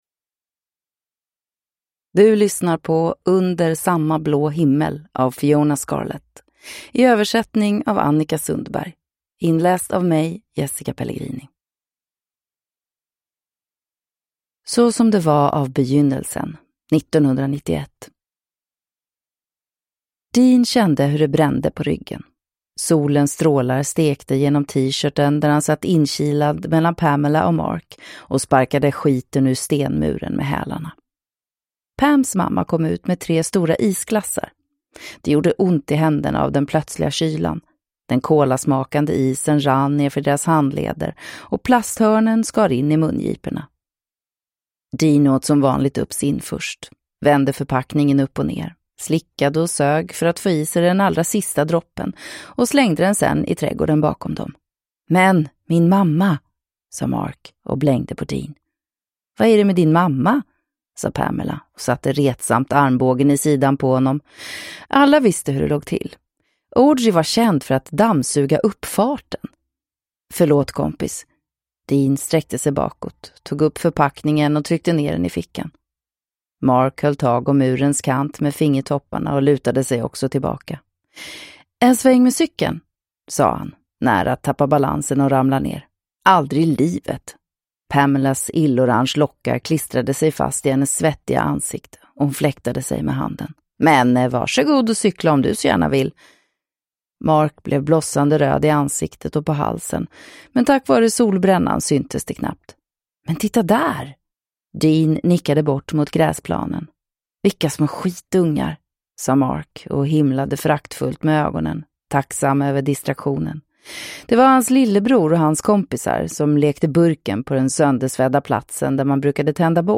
Under samma blå himmel (ljudbok) av Fíona Scarlett